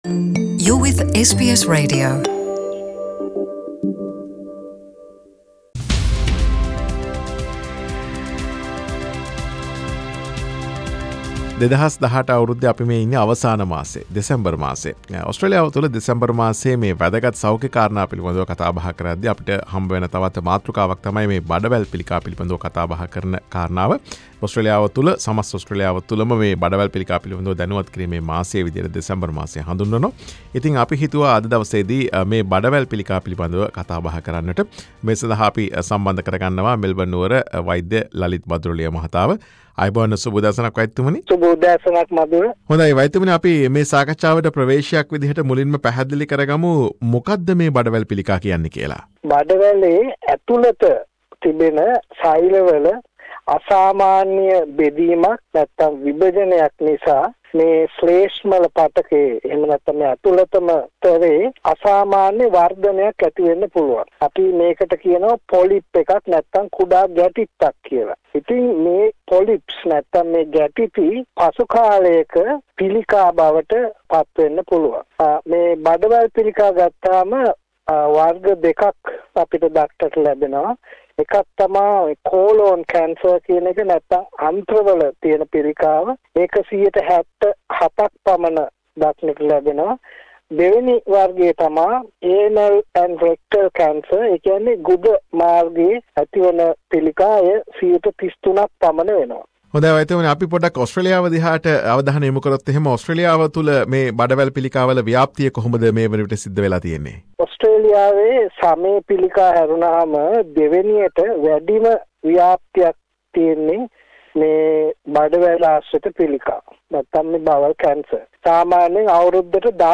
SBS සිංහල වැඩසටහන සිදු කළ වෛද්‍ය සාකච්ඡාව